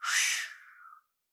deepExhale2.wav